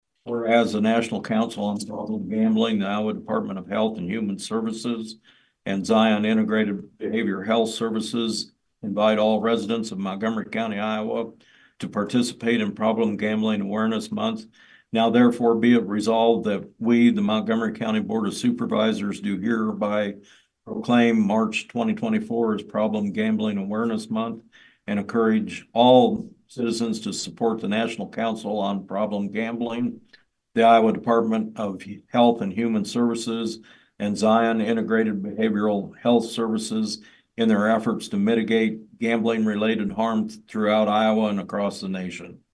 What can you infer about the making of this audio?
Montgomery County BOS mtg. 2-20-24